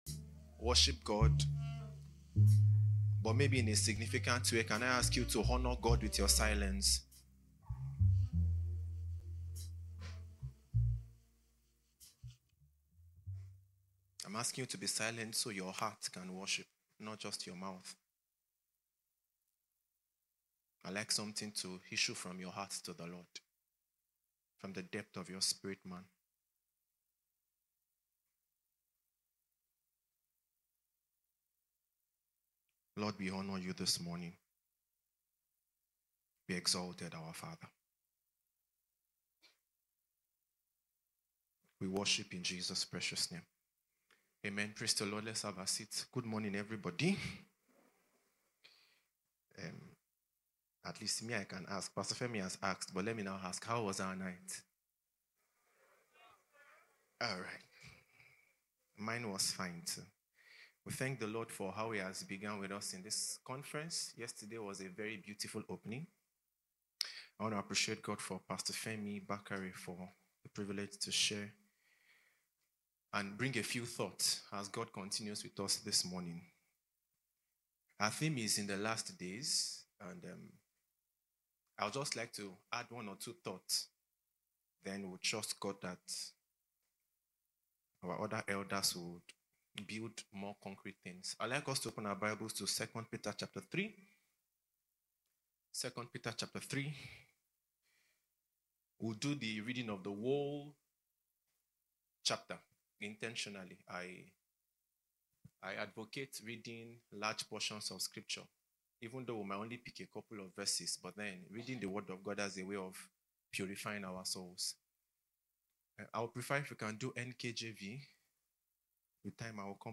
IRC PHC 2025 DAY 2 TEACHING 3 - Voice of Truth Ministries